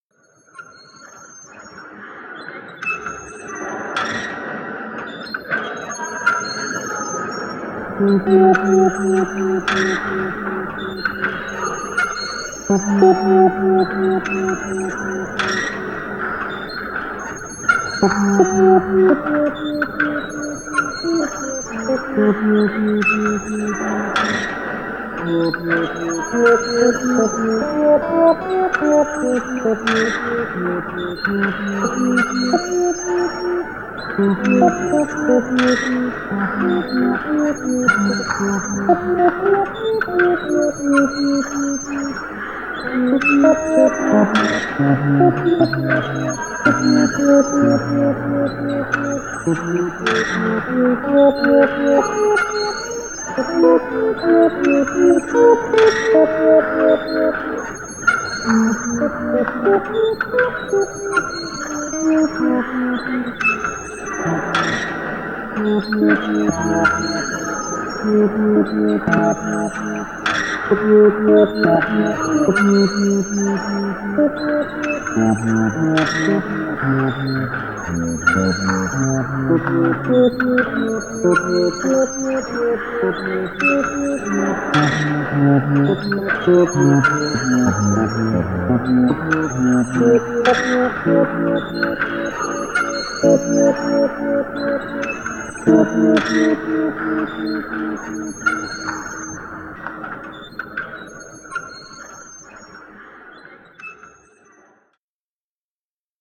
Gitarre / Geräusche